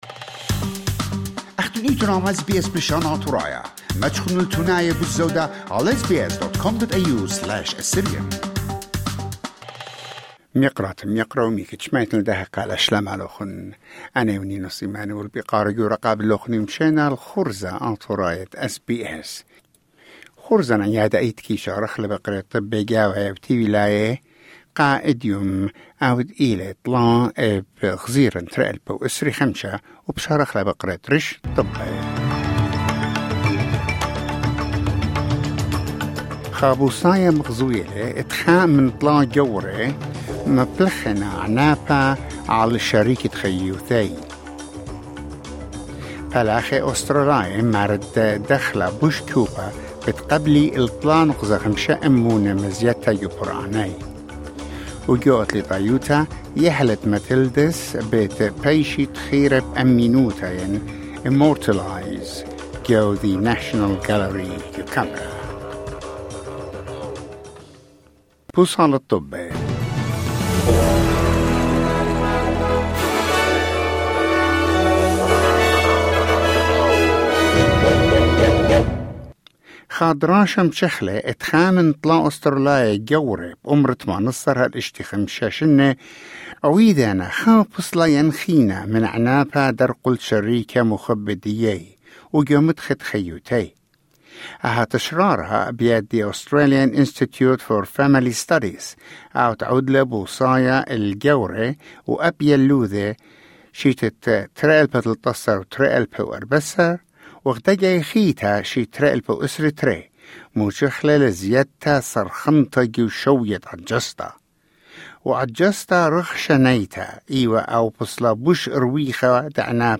SBS Assyrian news bulletin: 3 June 2025